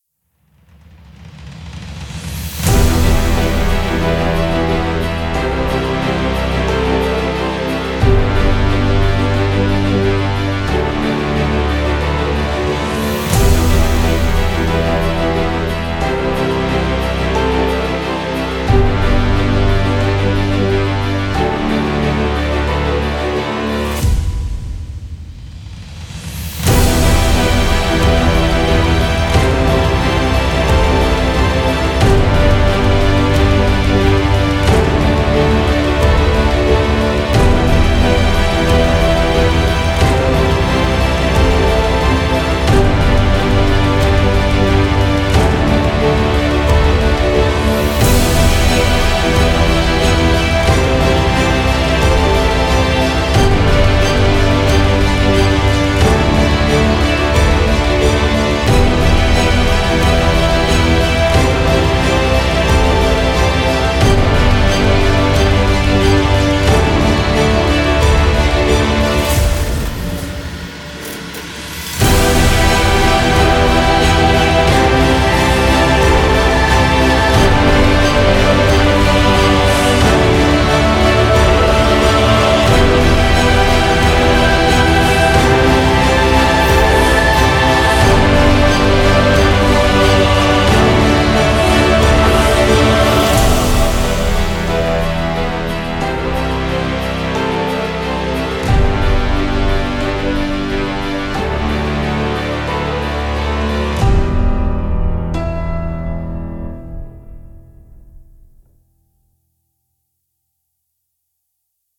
Genre: filmscore, trailer.